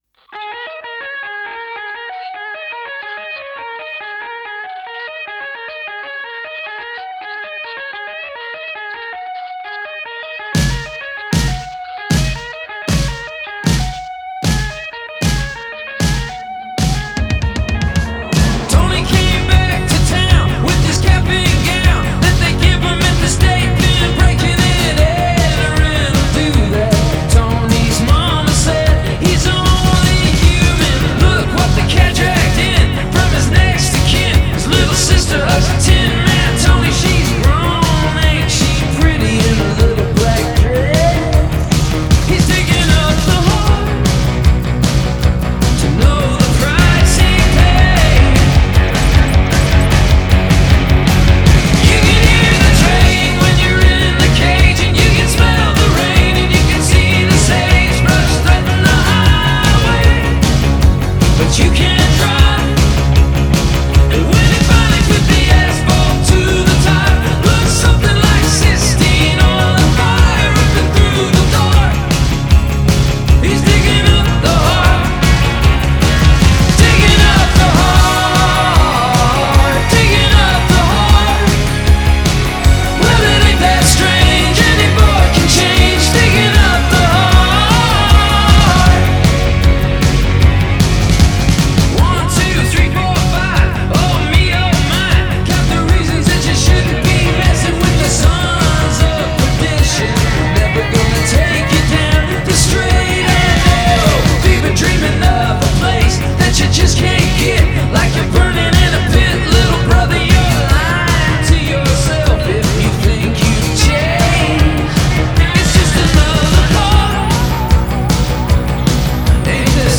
Genre: Pop Rock, Indie Rock